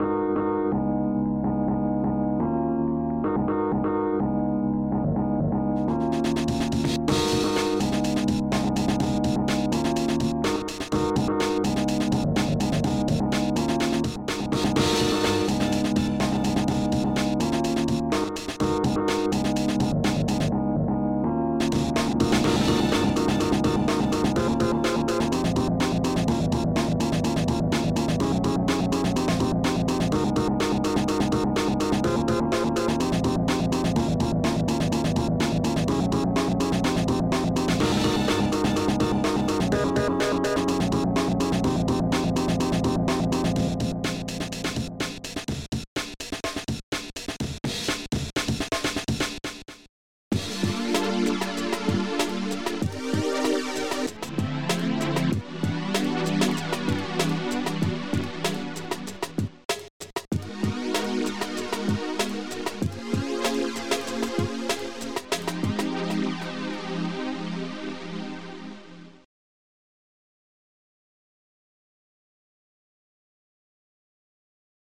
be warned like most ofthis shit is just dnb/jungle